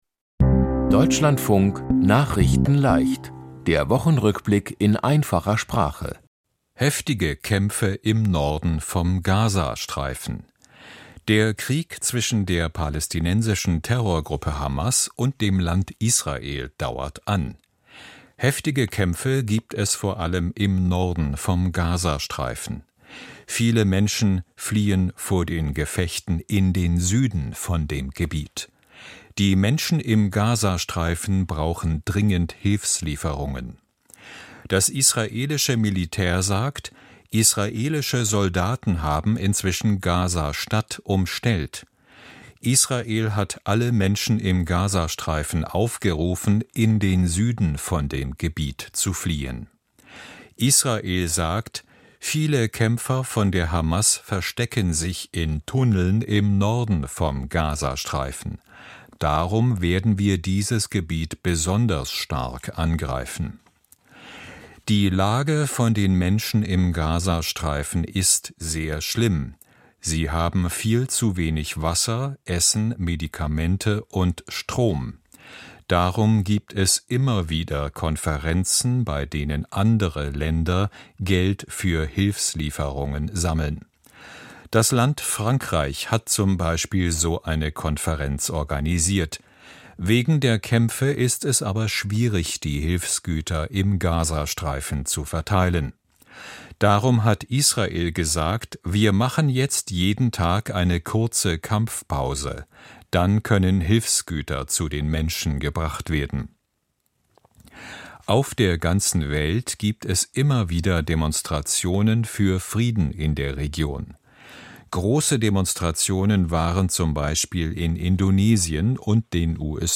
Die Themen diese Woche: Heftige Kämpfe im Norden vom Gaza-Streifen, Einigung beim Thema Migration, Erinnerung an 9. November, Viele Tote bei Erdbeben in Nepal, Streit in Hollywood beendet und DFB trennt sich von Voss-Tecklenburg. nachrichtenleicht - der Wochenrückblick in einfacher Sprache.